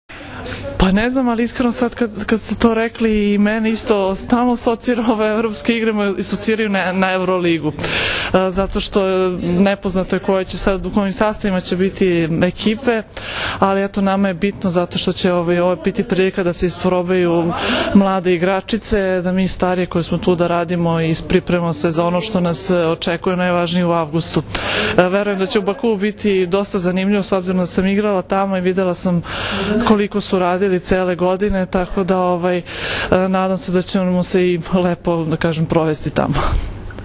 Na aerodromu “Nikola Tesla” jutros je održana konferencdija za novinare, na kojoj su se predstavnicima medija obratili Zoran Terzić, prvi trener seniorki Srbije, i Jelena Nikolić, kapiten Srbije.
IZJAVA JELENE NIKOLIĆ 2